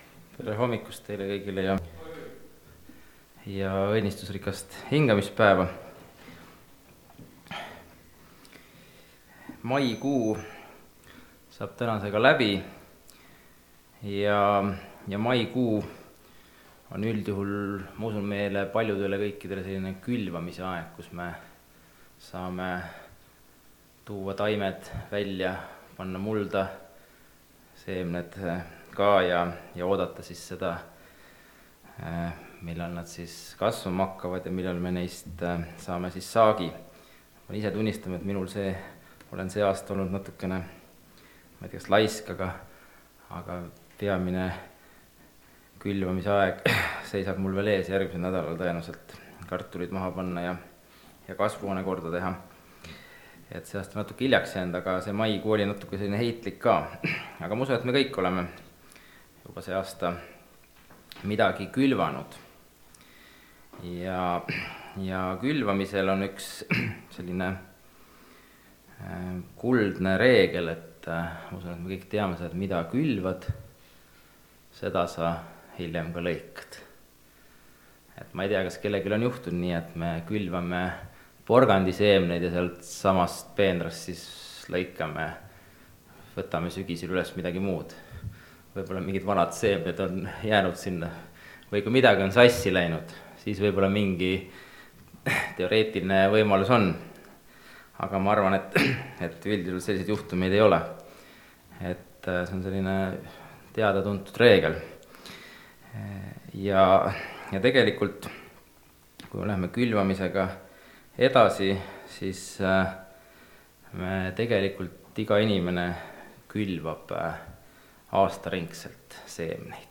seda lõikad (Rakveres)
Jutlused